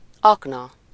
Ääntäminen
Vaihtoehtoiset kirjoitusmuodot (murteellinen) akona Ääntäminen Tuntematon aksentti: IPA: /ˈɒknɒ/ Haettu sana löytyi näillä lähdekielillä: unkari Käännös Ääninäyte 1. mina {en} Luokat Aseet Substantiivit